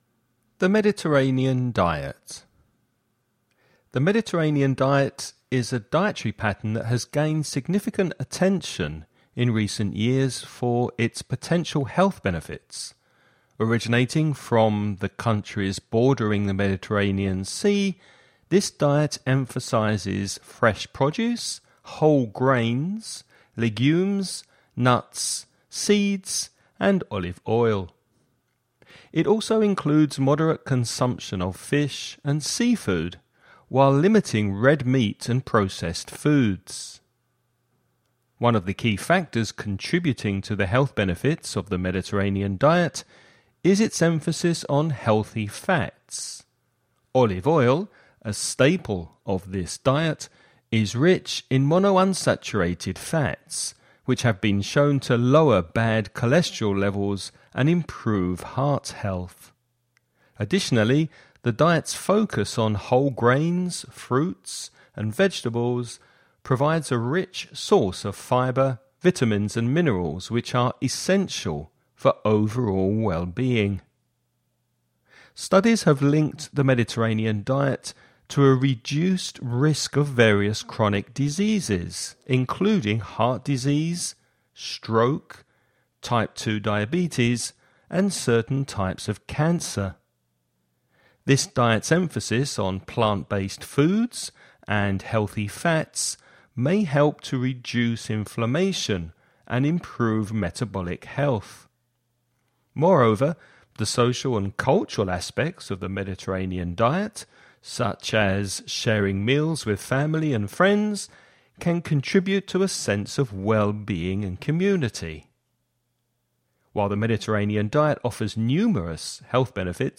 Listening Practice
You’re going to listen to a man talking about the Mediterranean diet.